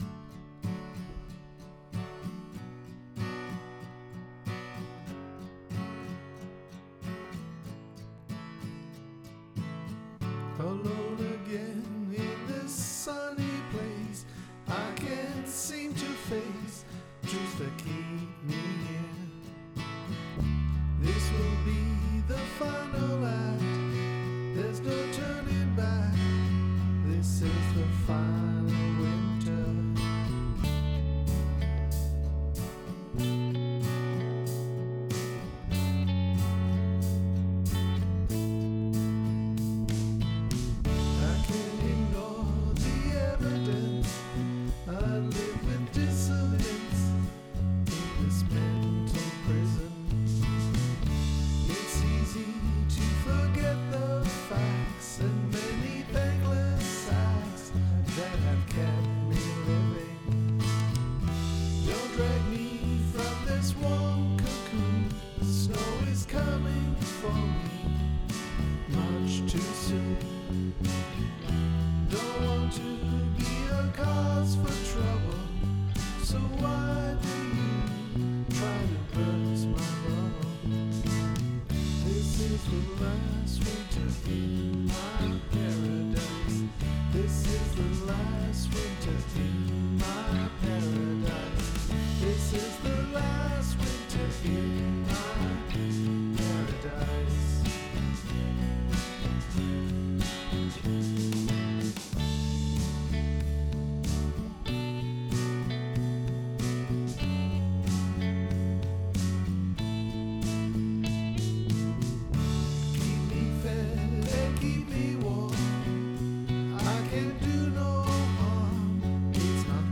It is stylistically different and a respectful attempt to indulge myself in being blatantly influenced as best I can by the flavour and feel of Crowded House, a band whose music I love.